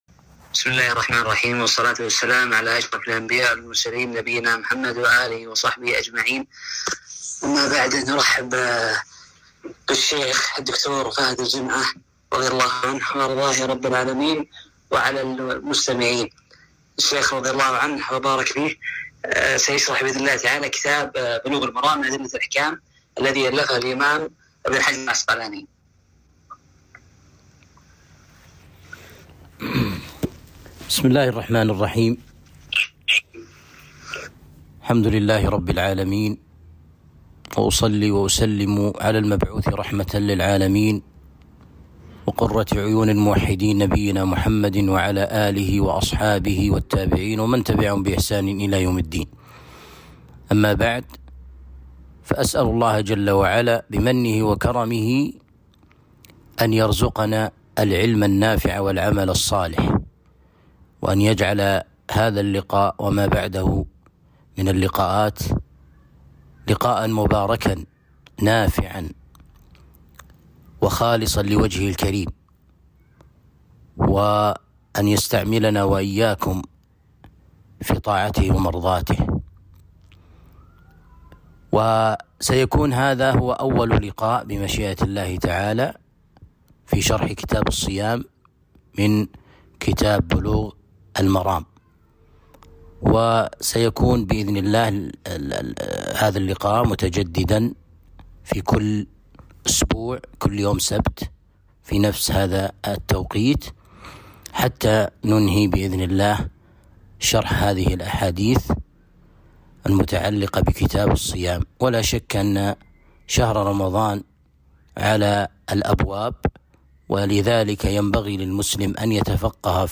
الدرس الأول شرح كتاب الصيام من بلوغ المرام